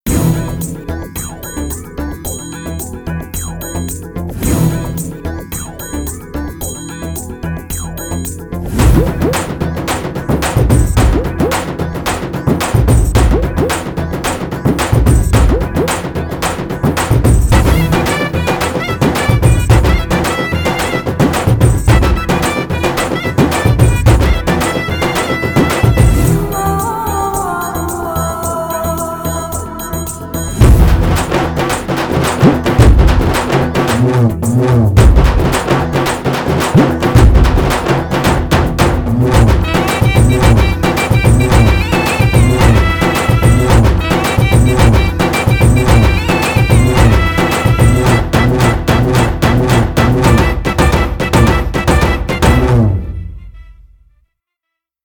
Lyricist: Instrumental